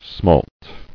[smalt]